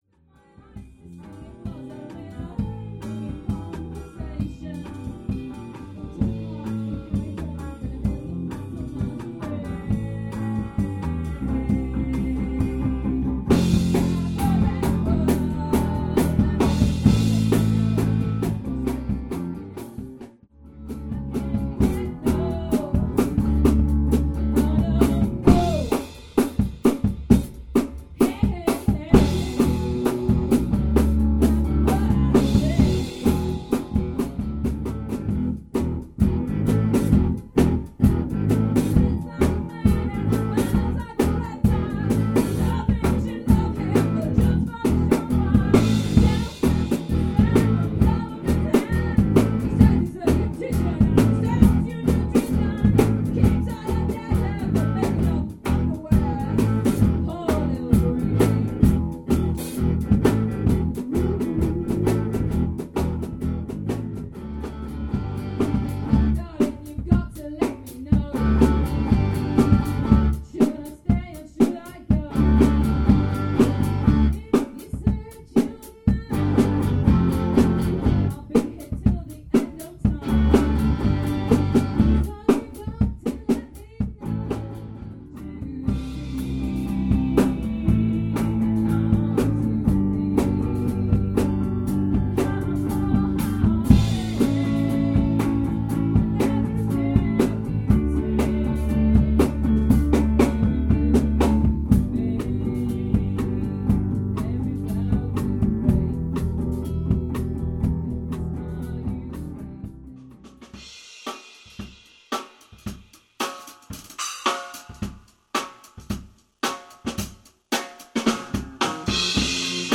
MulberryBlueinRehearsal.mp3